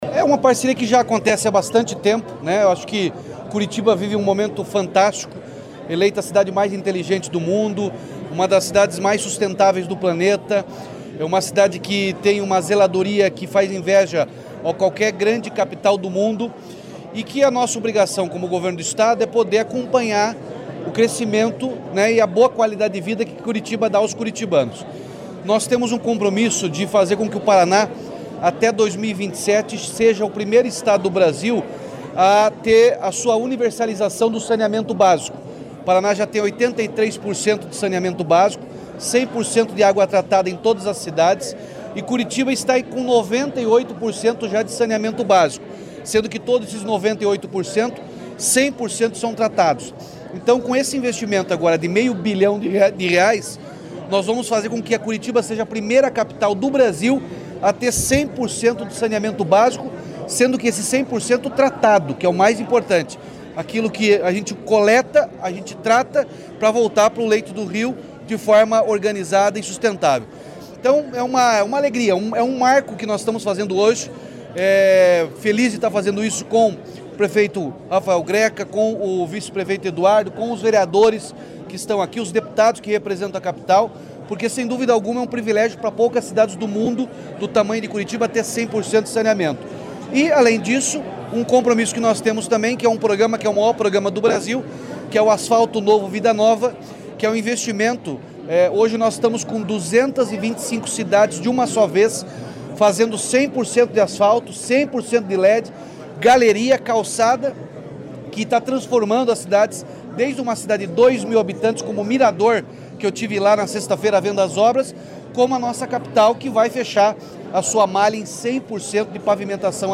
Sonora do governador Ratinho Junior sobre os anúncios feitos para Curitiba e Região Metropolitana